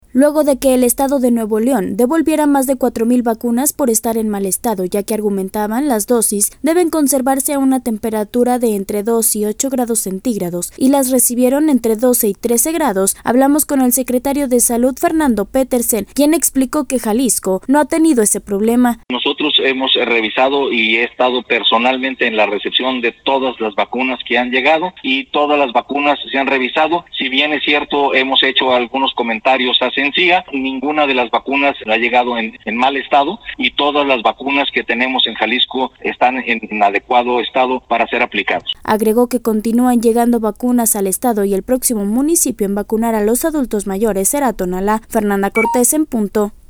Luego de que el estado de Nuevo León, devolviera más de cuatro mil vacunas por estar en mal estado, ya que argumentaban, las dosis deben conservarse a una temperatura de entre 2 y 8 grados centígrados, y las recibieron entre 12 y 13 grados, hablamos con el secretario de Salud, Fernando Petersen, quien explicó qué en Jalisco no han tenido ese problema.